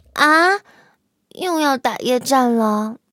M6夜战语音.OGG